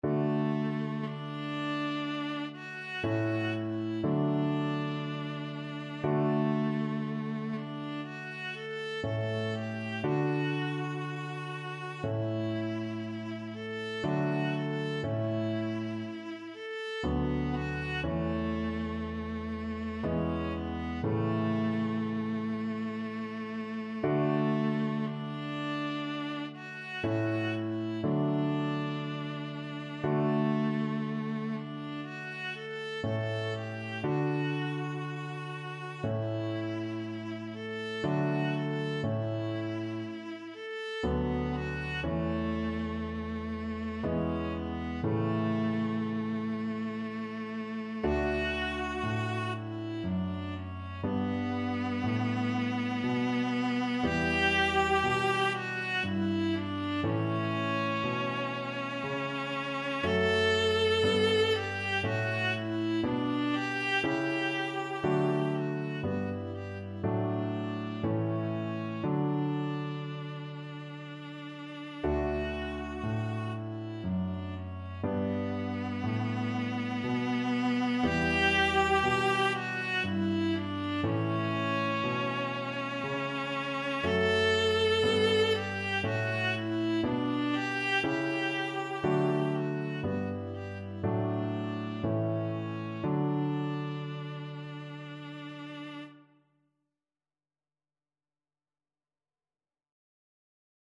Play (or use space bar on your keyboard) Pause Music Playalong - Piano Accompaniment Playalong Band Accompaniment not yet available transpose reset tempo print settings full screen
Viola
D major (Sounding Pitch) (View more D major Music for Viola )
Lento e legato
3/4 (View more 3/4 Music)
Classical (View more Classical Viola Music)